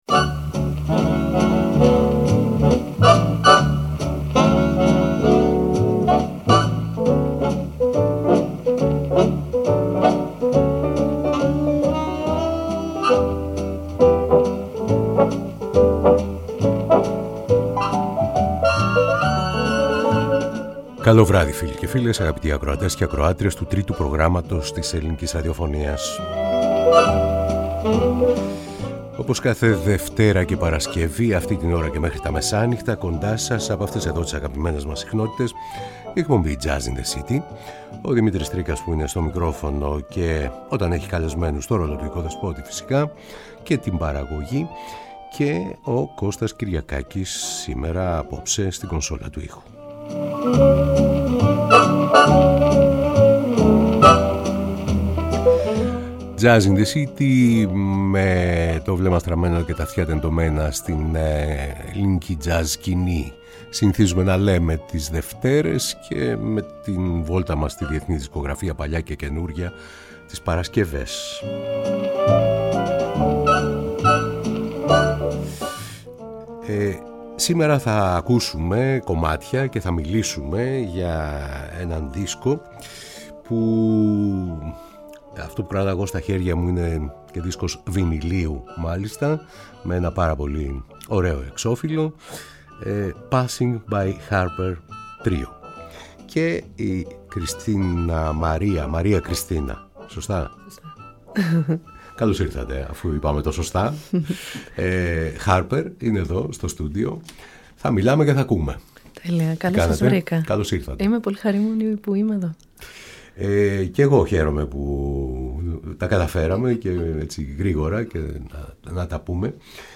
Τζαζ